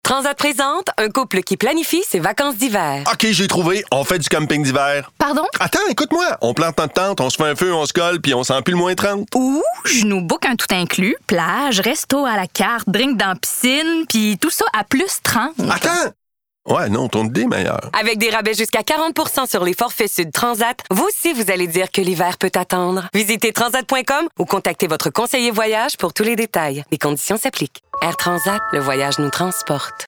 voix
Publicité - Voix Personnage 1